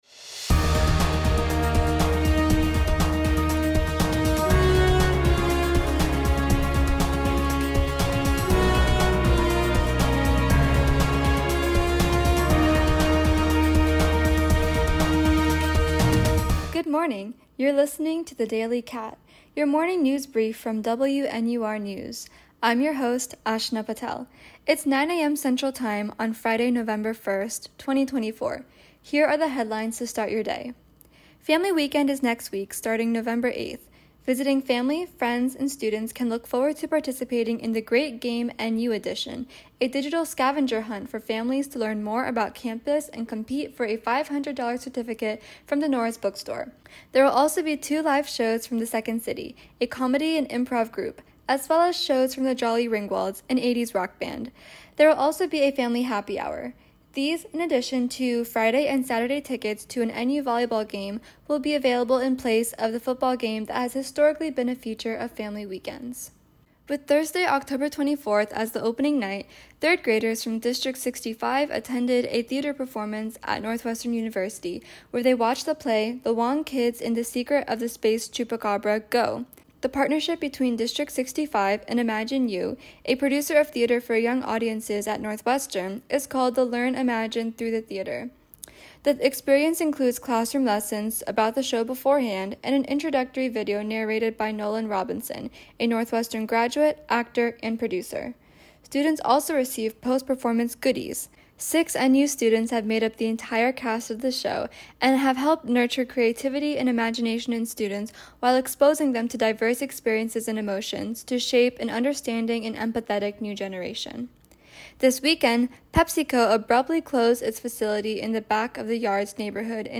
November 1, 2024 Northwestern Family Weekend coming up, NU partnership with District 65 for live theater performances, PepsiCo Chicago location shut down, Michael Madigan, Dodgers winning the World Series, and flash floods in Spain. WNUR News broadcasts live at 6 pm CST on Mondays, Wednesdays, and Fridays on WNUR 89.3 FM.